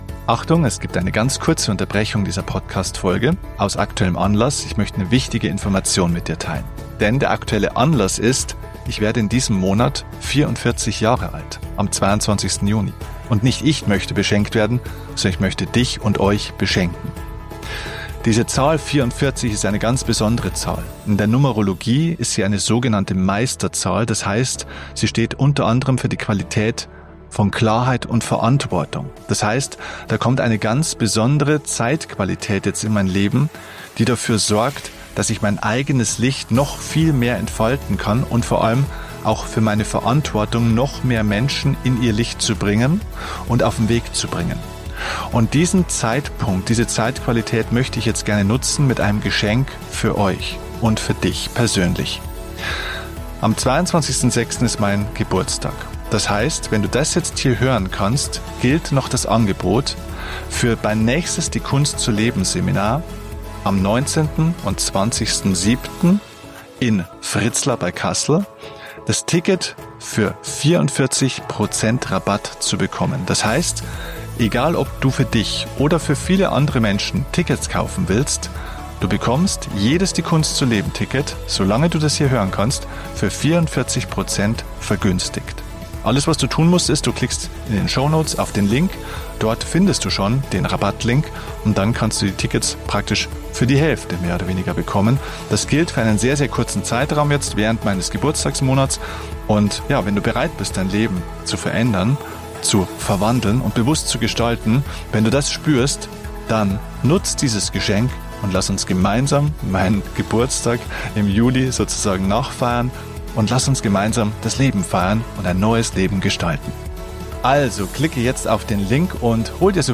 Wie immer bei Soul Talk gibt es kein Skript, keine vorgefertigten Meinungen – nur zwei Freunde, die sich ehrlich und ungefiltert über ihre Erfahrungen und Gedanken austauschen. Wir teilen, warum Zucker so verführerisch ist und gleichzeitig wie ein verstecktes Gift wirken kann.